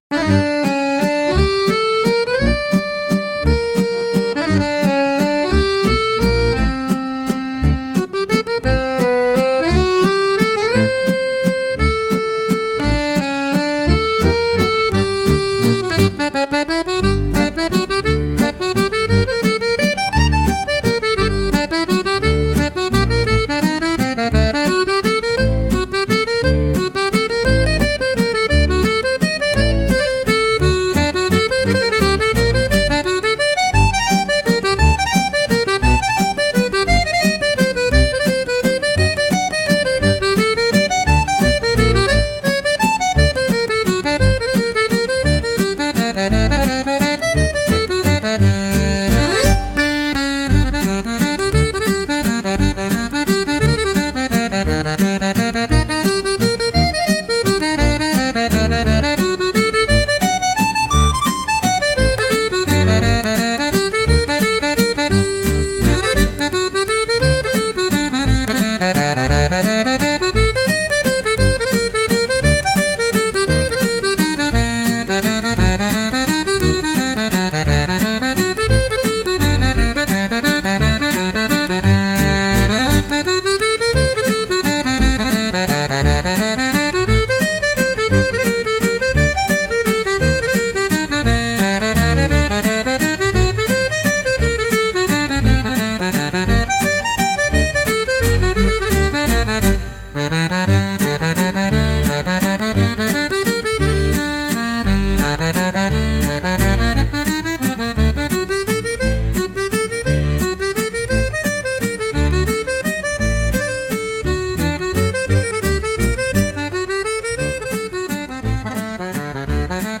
latest Suno creation. a polka waltz with heavy accordeon. it goes a little wild but that's OK.
It's almost like a tango.